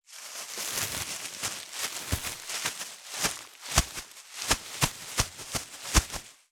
652コンビニ袋,ゴミ袋,スーパーの袋,袋,買い出しの音,ゴミ出しの音,袋を運ぶ音,
効果音